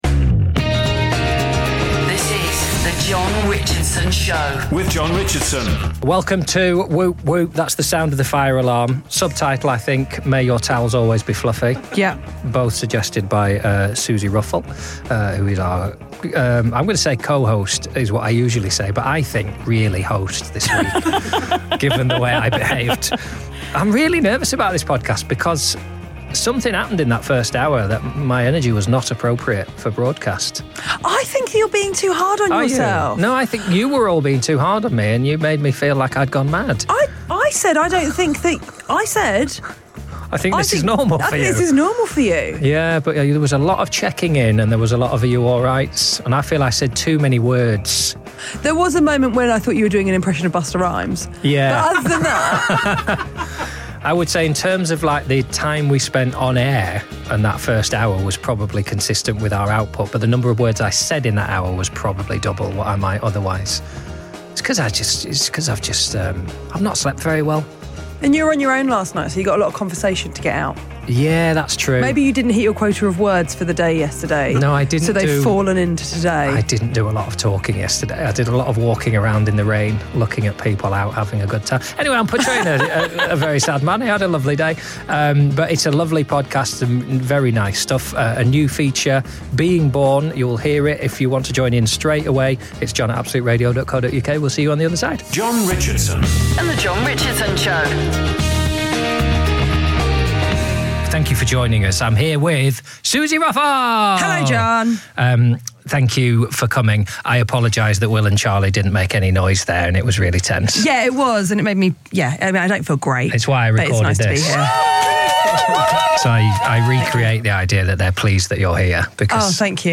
AKA - Whoop, Whoop! It's the sound of the fire alarm!
Join Jon Richardson & Suzi Ruffell for a unique take on Saturday mornings...